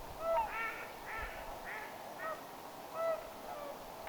joilla itäisilla laulujoutsenilla,
on olemassa kuin haukahtava ääni
myos_aikuisilla_ilm_itaisilla_laulujoutsenilla_on_tuollainen_aanityyppi.mp3